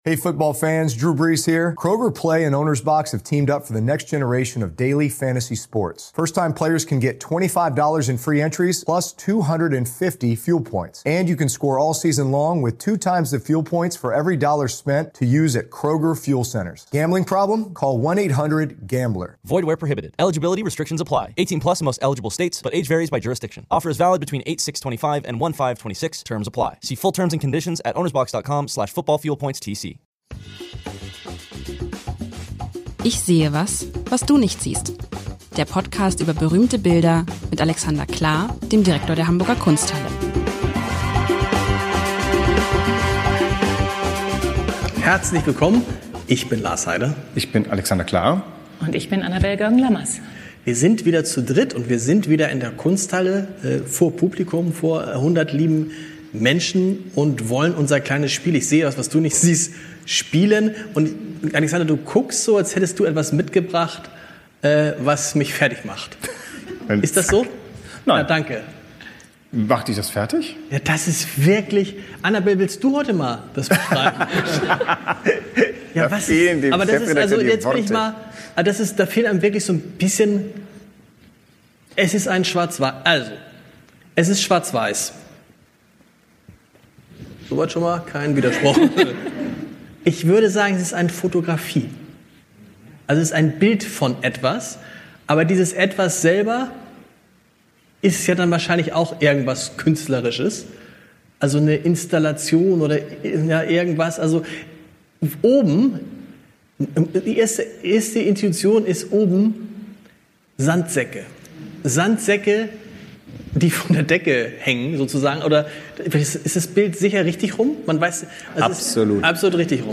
Live-Mitschnitt vor Publikum aus der Kunsthalle mit einem besonderen Gast.